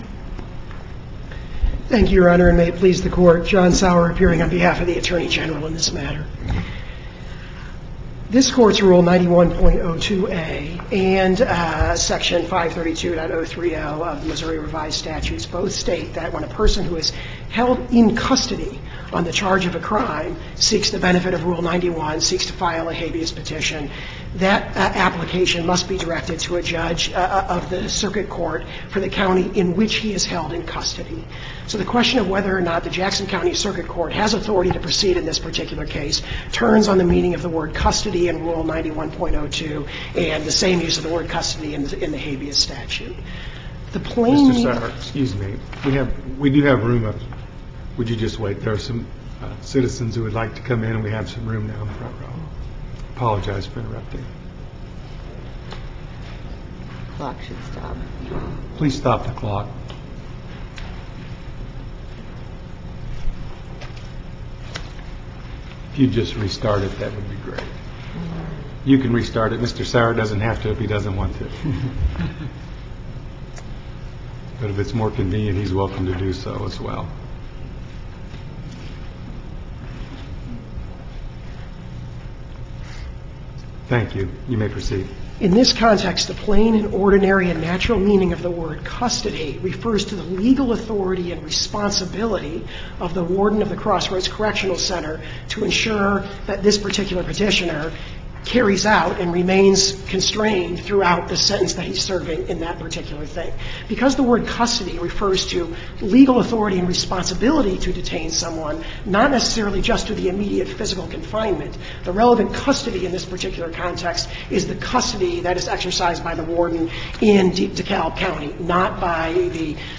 MP3 audio file of arguments in SC96739